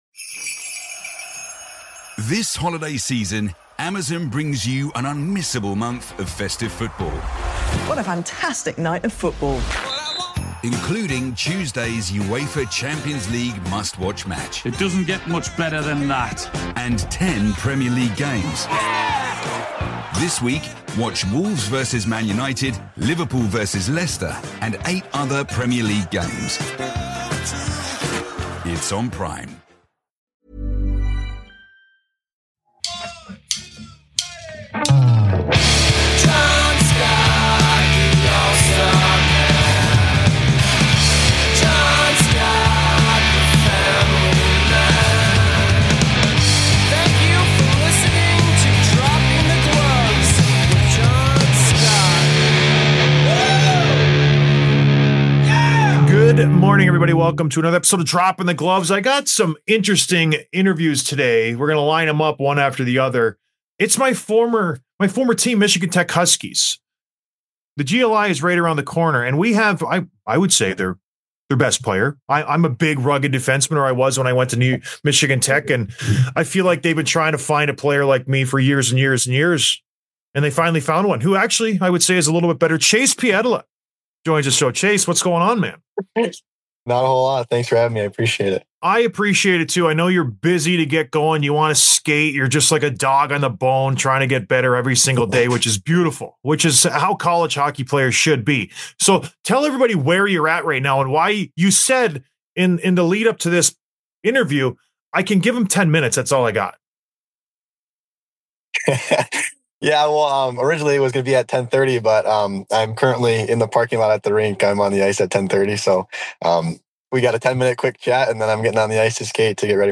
Download - 590: Interview with Conor Garland, Vancouver Canucks | Podbean